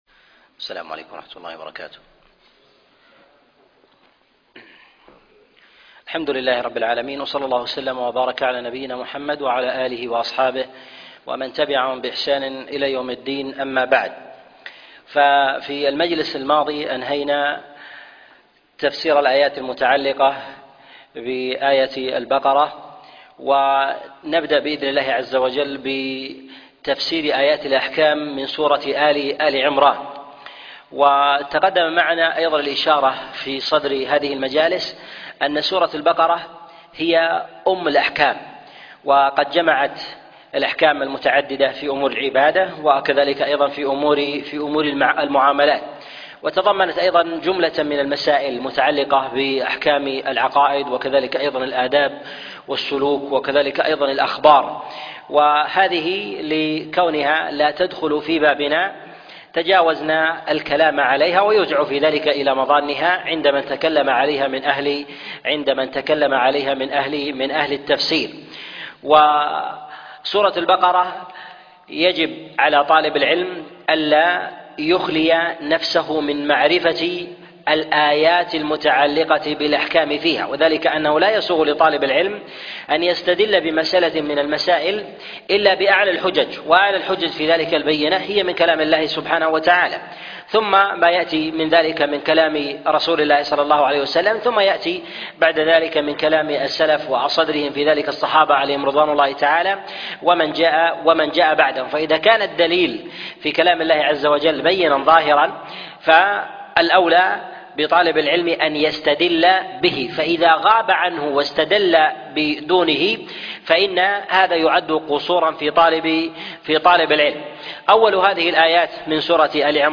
تفسير سورة آل عمران 1 - تفسير آيات الأحكام - الدرس السادس والأربعون